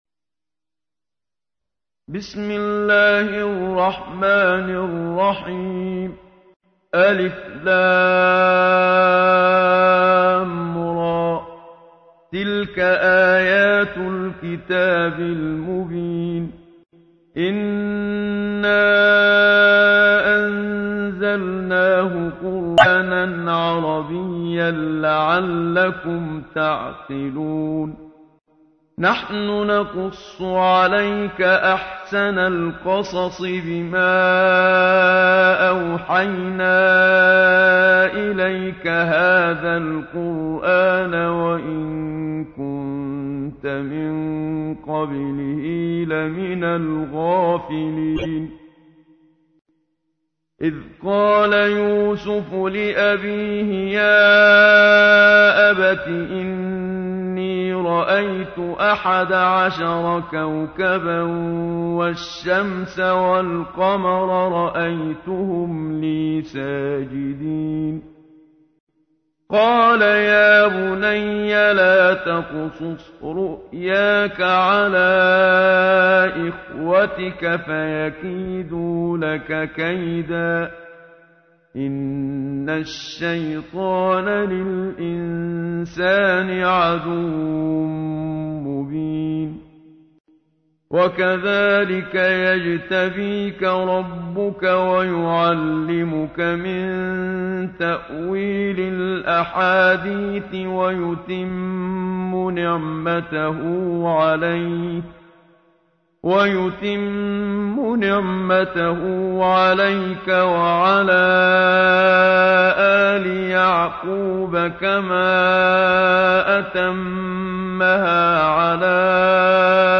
تحميل : 12. سورة يوسف / القارئ محمد صديق المنشاوي / القرآن الكريم / موقع يا حسين